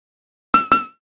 fire-1.ogg.mp3